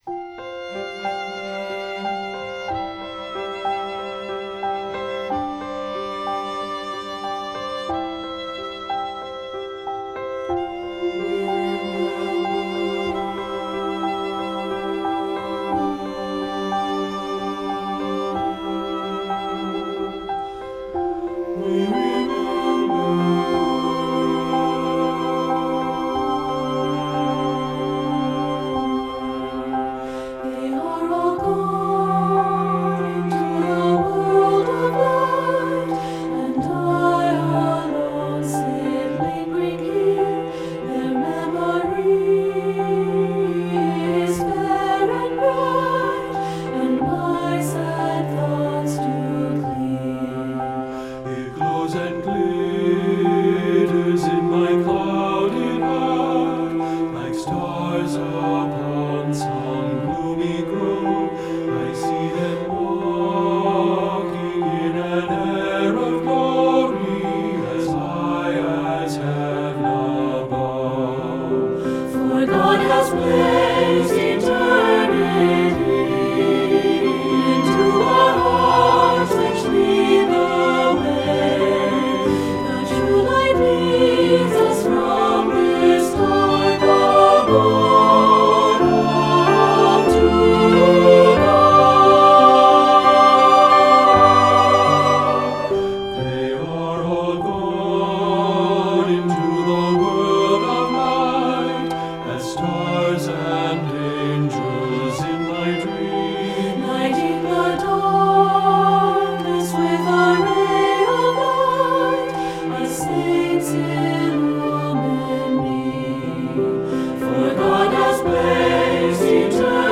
Voicing: SAB, Piano and optional Violin and Cello